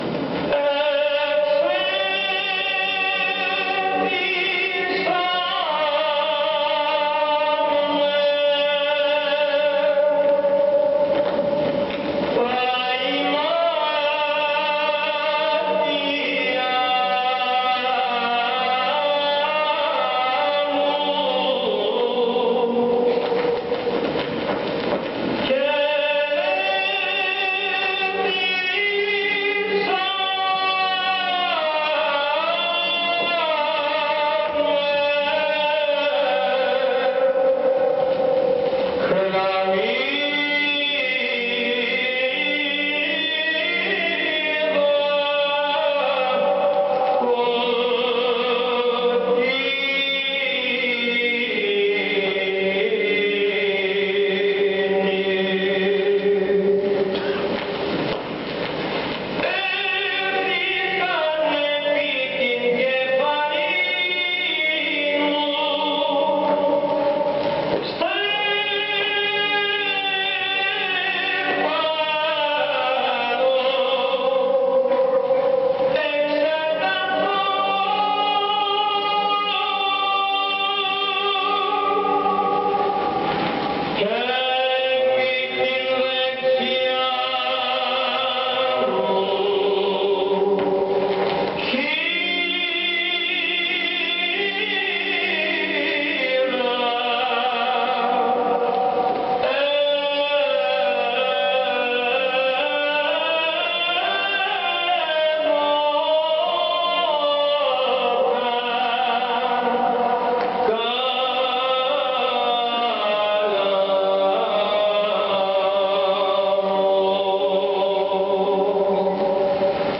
ψάλλει ο αείμνηστος πρωτοψάλτης